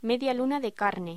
Locución: Media luna de carne
voz